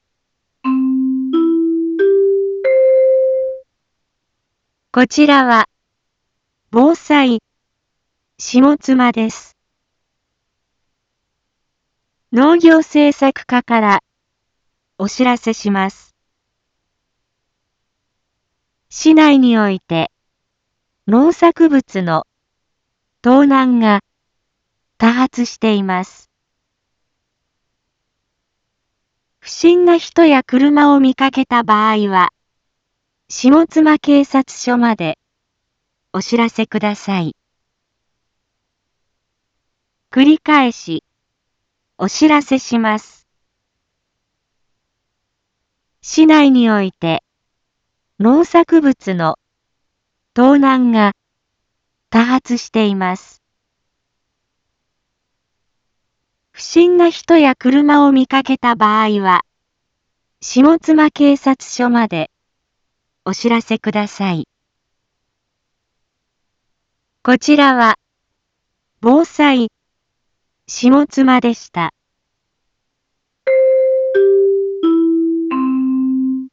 Back Home 一般放送情報 音声放送 再生 一般放送情報 登録日時：2025-09-16 18:01:37 タイトル：農作物の盗難被害について インフォメーション：こちらは、ぼうさいしもつまです。